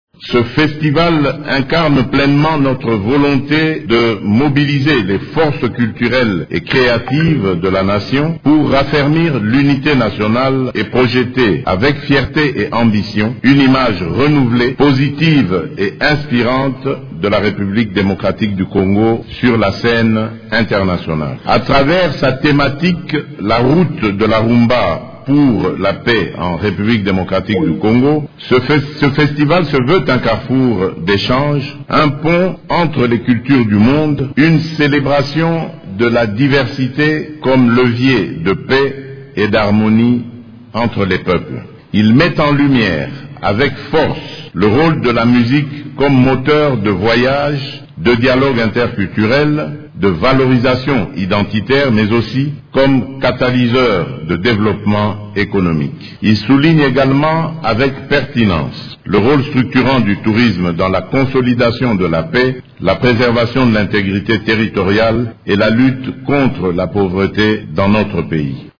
La première édition du Festival mondial de la musique et du tourisme a ouvert ses portes mercredi 16 juillet au Centre culturel et artistique pour les pays de l’Afrique centrale à Kinshasa.
À cette occasion, le Chef de l’État, Félix Tshisekedi a exprimé le souhait de voir cet événement contribuer à raffermir l’unité nationale et à redorer l’image de la RDC.